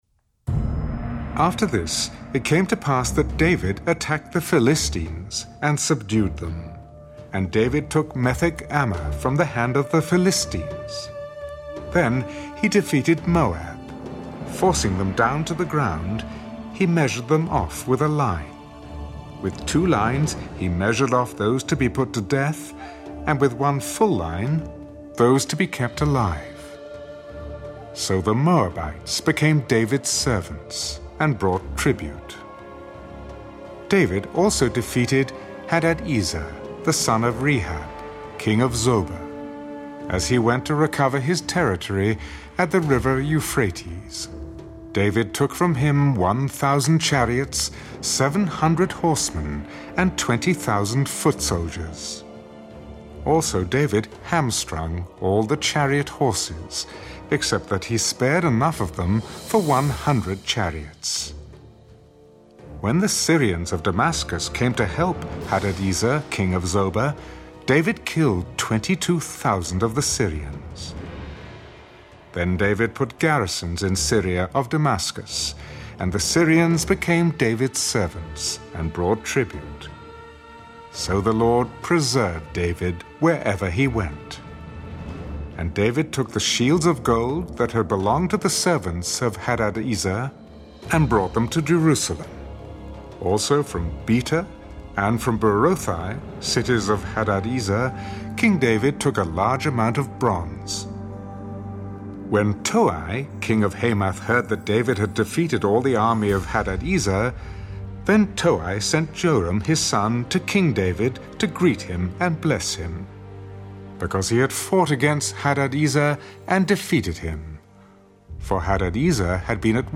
(Audio Bible)